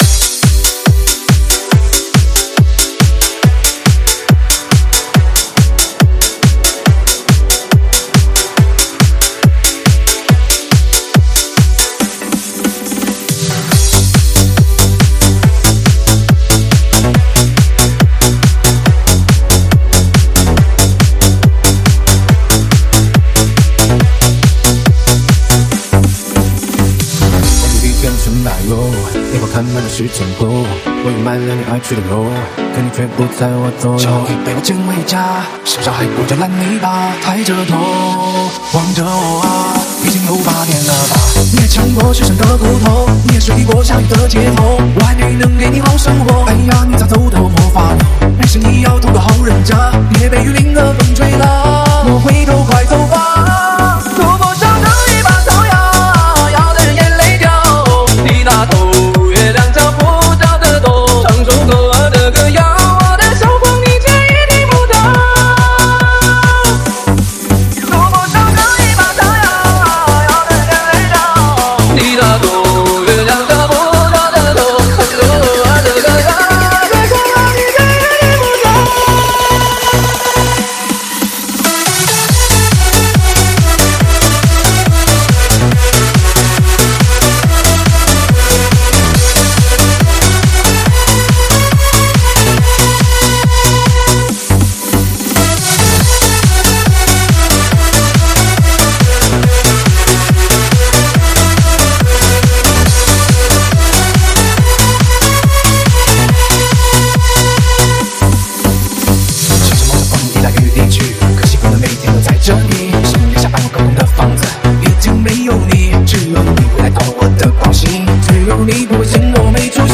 试听文件为低音质，下载后为无水印高音质文件 M币 10 超级会员 M币 5 购买下载 您当前未登录！